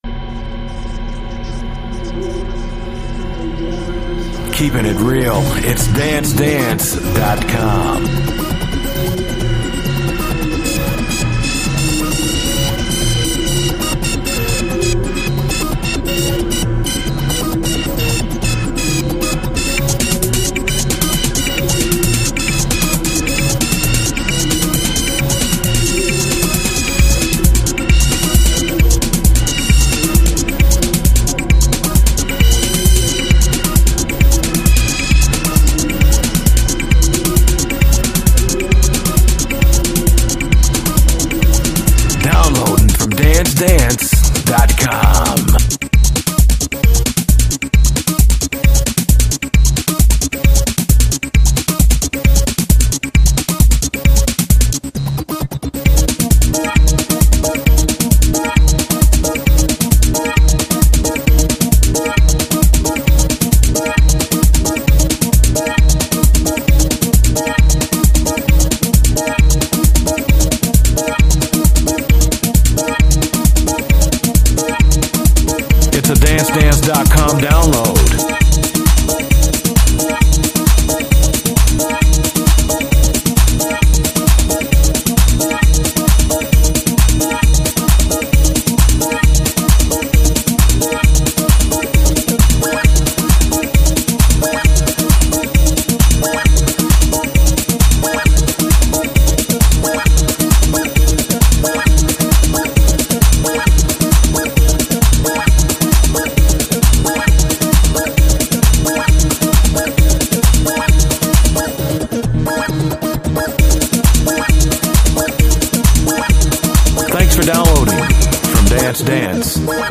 techno record